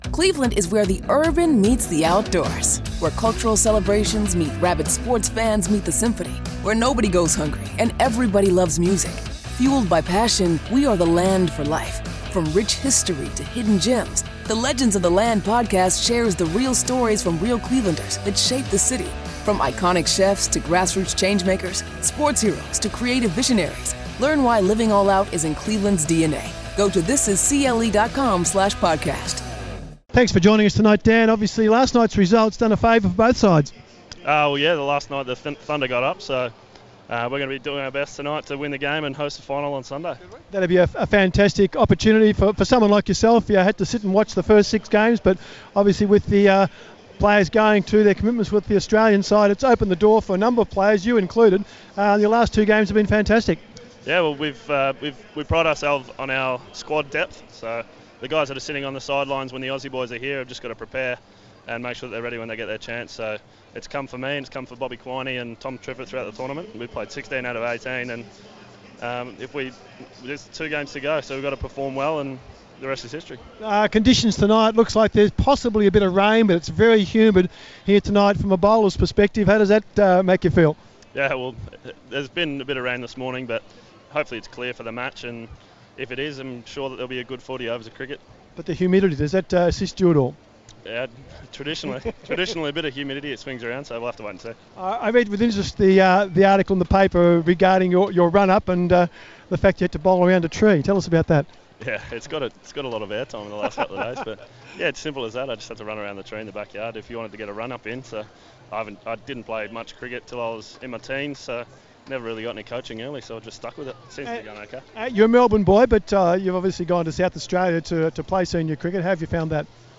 INTERVIEW: Melbourne Stars paceman Dan Worrall chats before their semi-final clash against Perth.